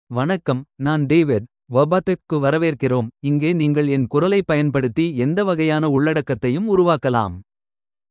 David — Male Tamil AI voice
David is a male AI voice for Tamil (India).
Voice sample
Male
David delivers clear pronunciation with authentic India Tamil intonation, making your content sound professionally produced.